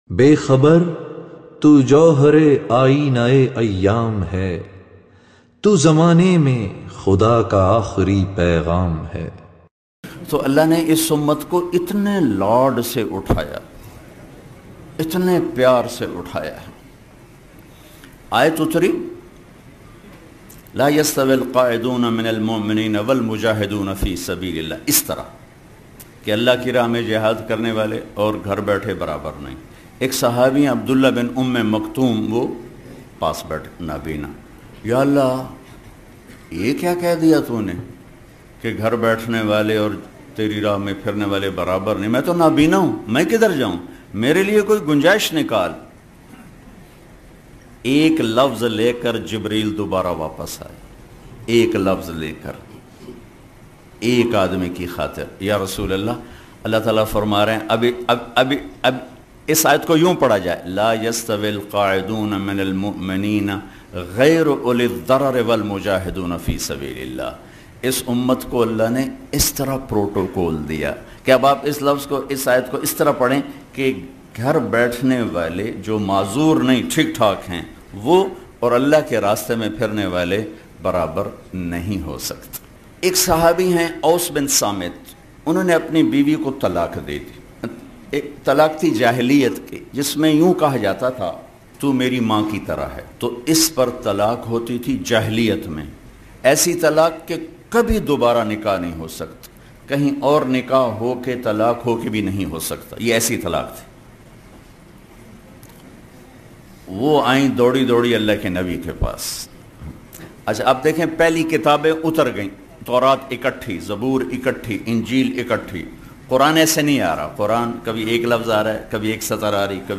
Hazrat Jibreel A.S Aur Nabina Sahabi Maulana Tariq Jameel Short Clip Bayan.mp3